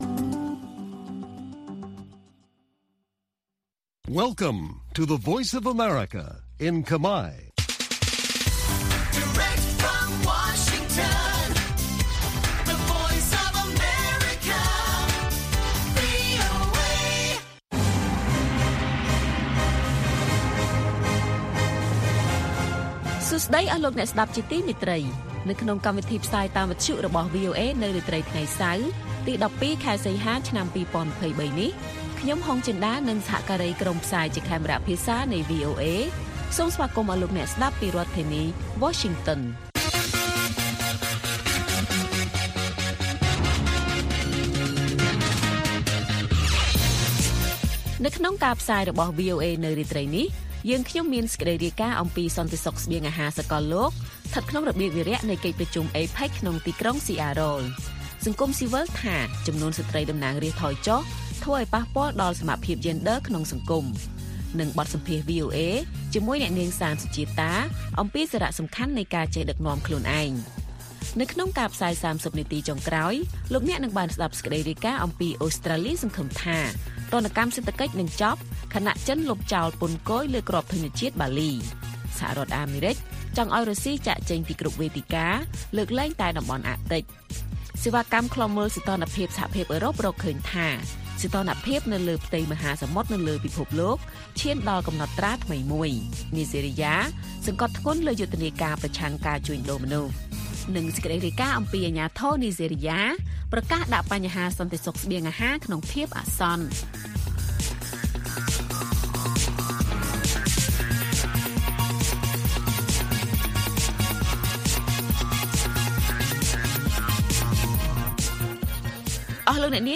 ព័ត៌មានពេលរាត្រី ១២ សីហា៖ សន្តិសុខស្បៀងអាហារសាកលលោកស្ថិតក្នុងរបៀបវារៈនៃកិច្ចប្រជុំ APEC ក្នុងទីក្រុង Seattle